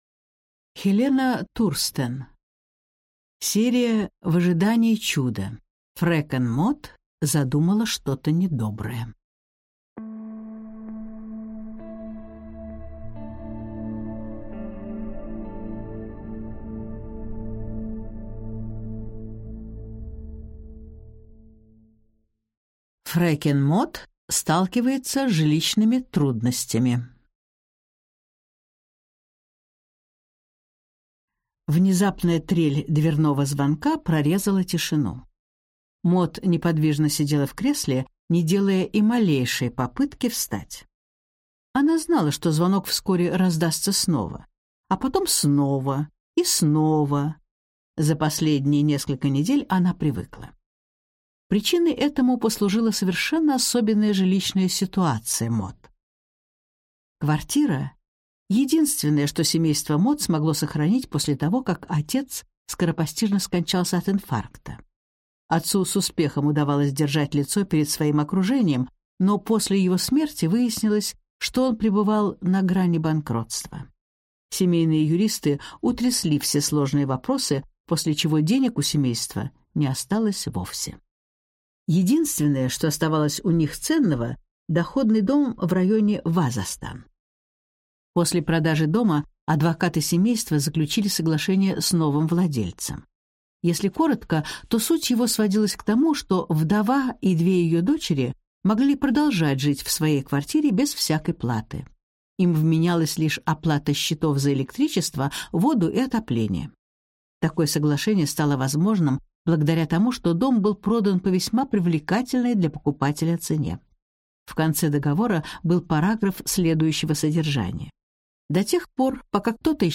Аудиокнига Фрекен Мод задумала что-то недоброе | Библиотека аудиокниг